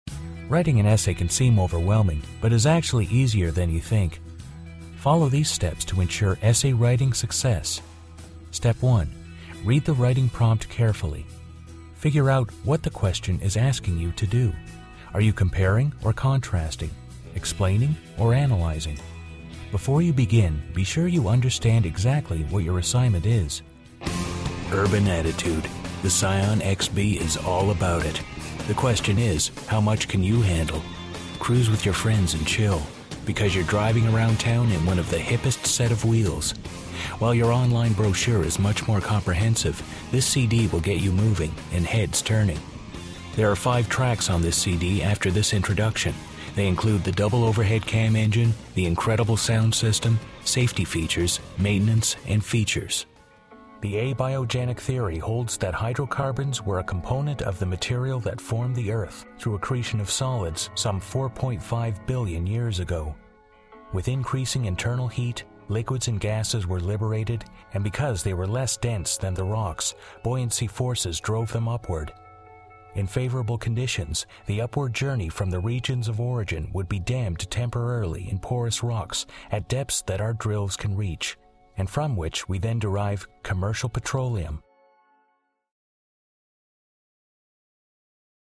-male non-union voice talent -resonant mid-range to bass -clear, warm and friendly -good dramatic range & comedic timing -ideally suited for narration, corporate, commercial work and audio books
Sprechprobe: eLearning (Muttersprache):